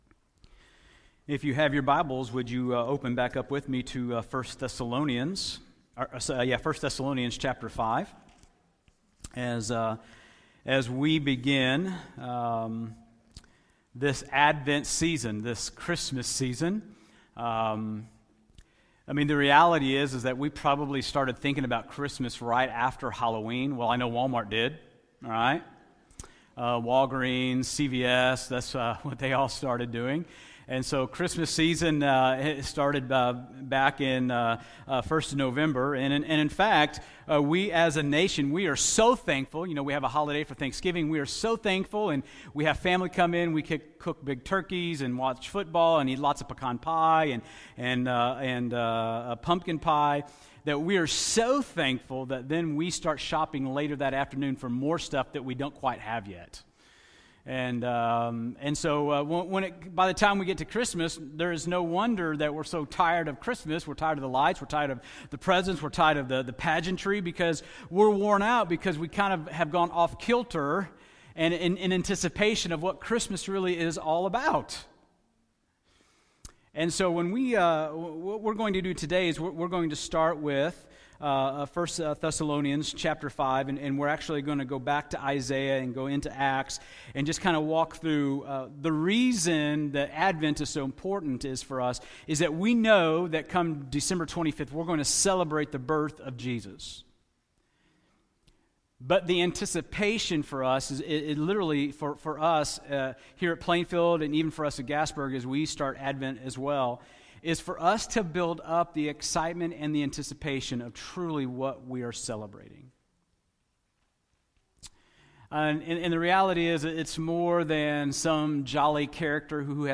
Sermon Audio 2017 December 3